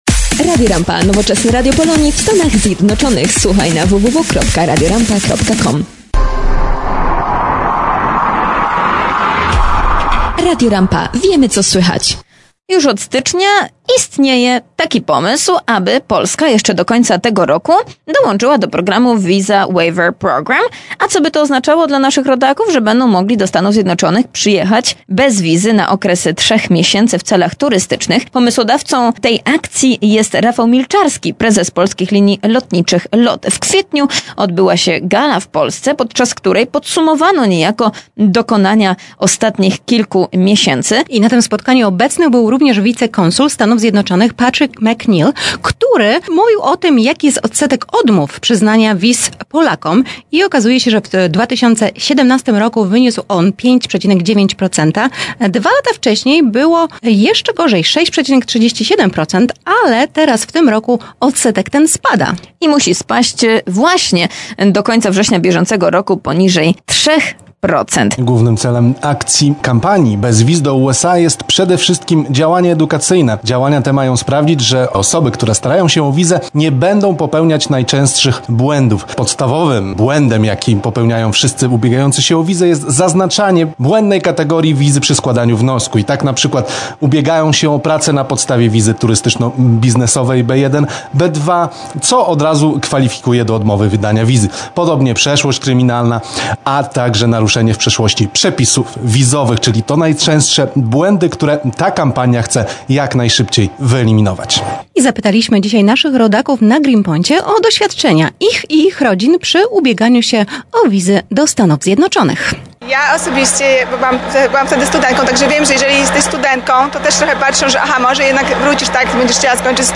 Ubieganie-sie-o-Wizy-Sonda.mp3